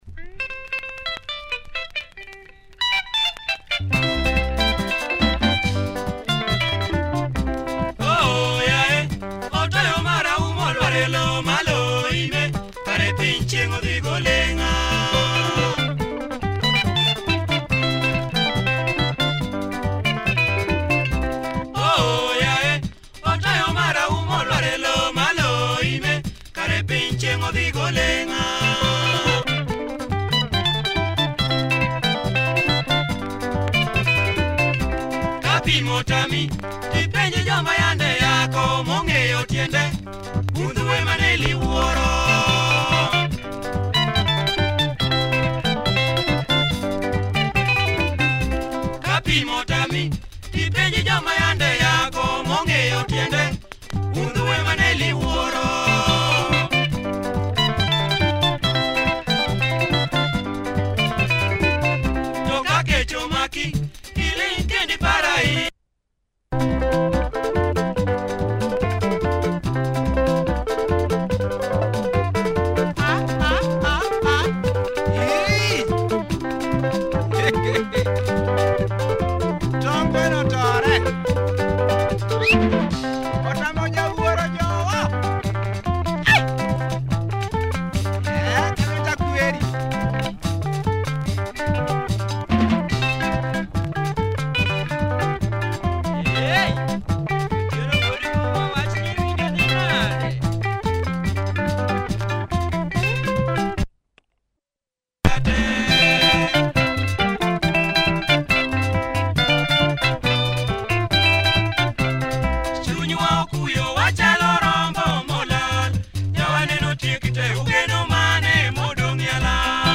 Super Luo bumper